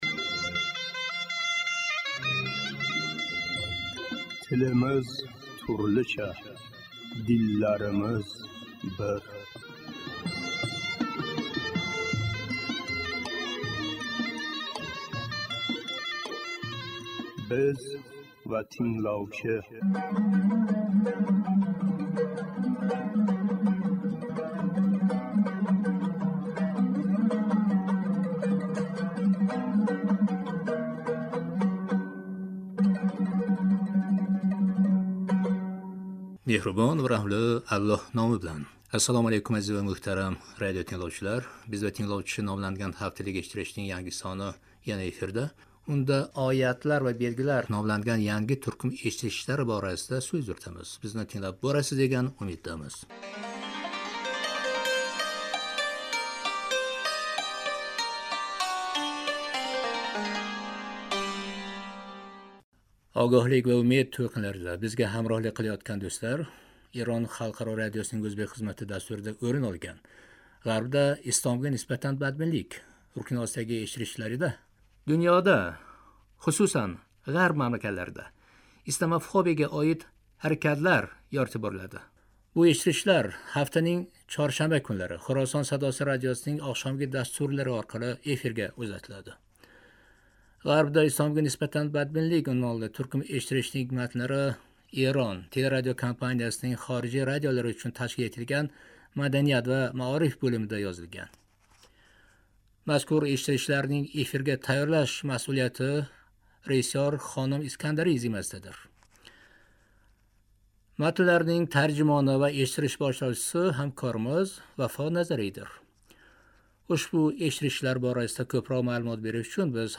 Ассалому алайкум, азиз ва муҳтарам радиотингловчилар !